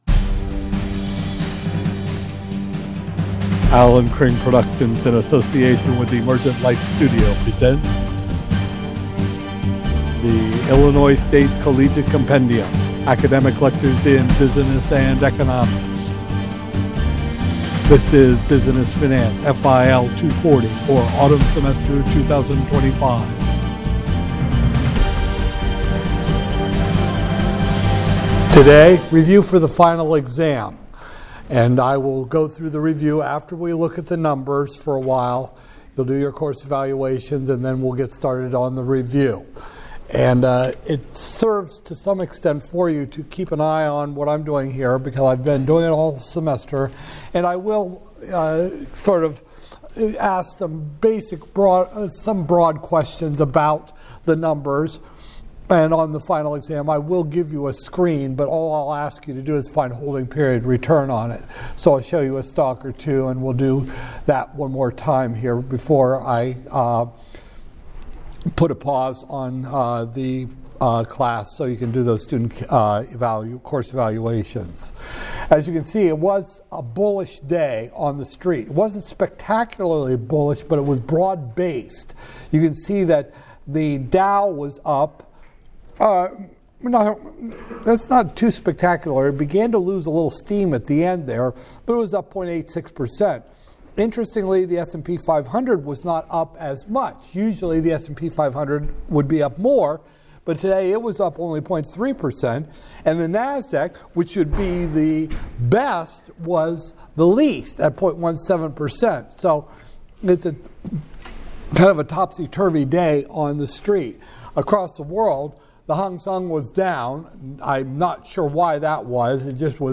Illinois State Collegiate Compendium - Business Finance, FIL 240-002, Spring 2025, Lecture 29